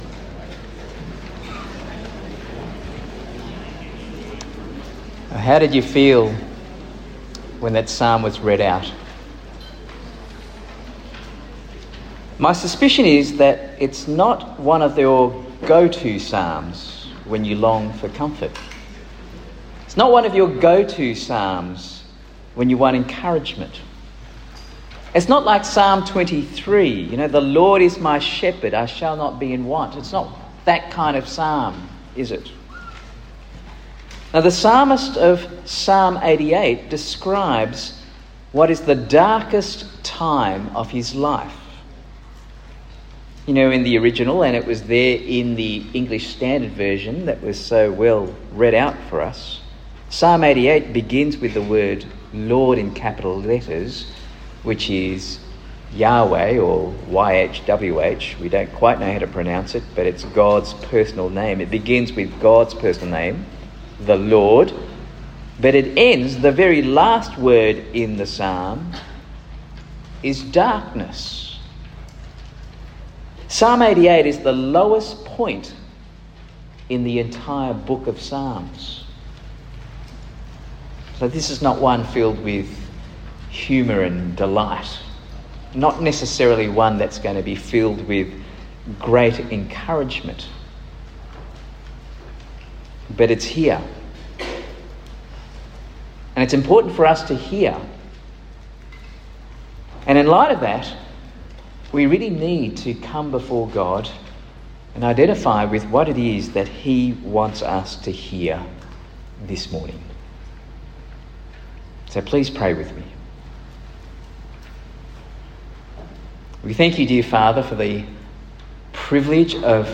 Talk 5 of 6 given at Launch 2025, a camp for school leavers keen to live for Jesus as they commence university life.